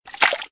TT_splash1.ogg